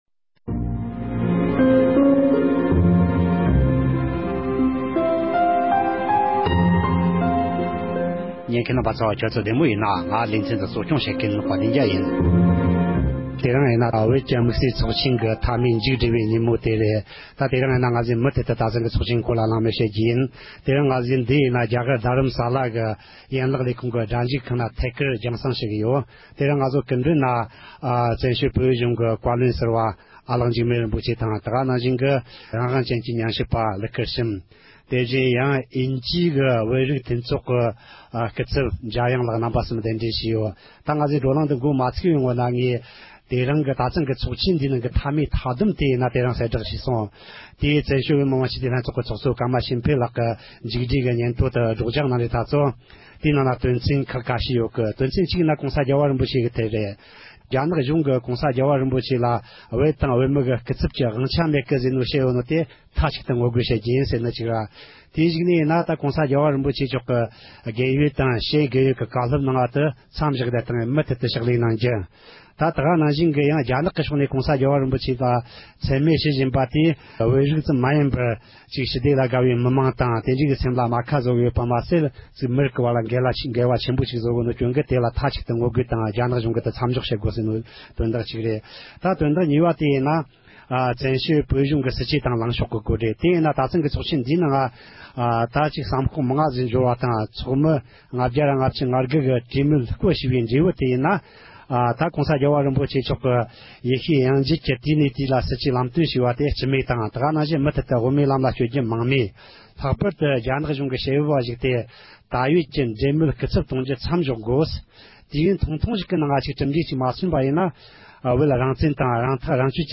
བཞུགས་སྒར་དུ་ཚོཌ་ཡོད་པའི་བོད་དོན་དམིཌ་བསལ་ཚོགས་ཆེན་གྱི་མཇུག་སྒྲིལ་ཉིན་མོར་ཚོཌ་བཅར་བ་ཁག་དང་ལྷན་དུ་ཚོགས་ཆེན་སྐོར་གླེང་མོལ།